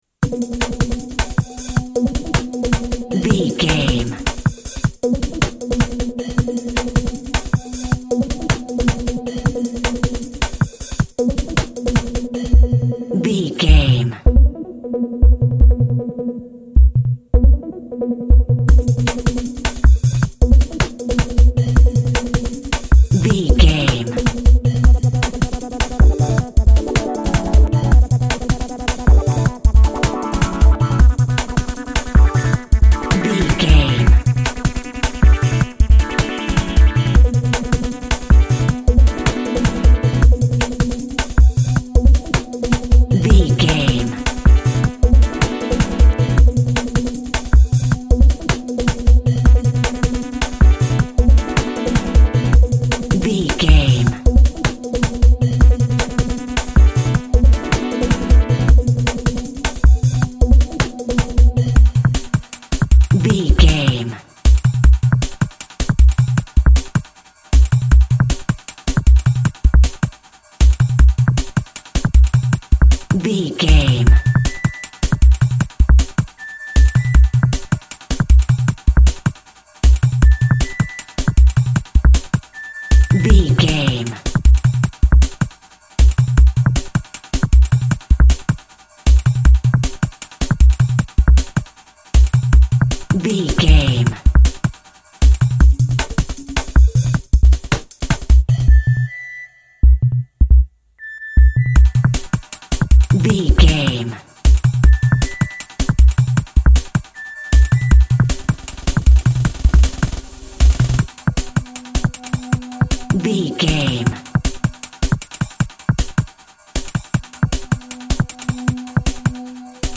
Epic / Action
Fast paced
Aeolian/Minor
Fast
intense
energetic
drums
drum machine
synthesiser
flute
house music
electronic
techno
trance
synth lead
synth bass
Synth Pads